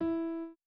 01_院长房间_钢琴_03.wav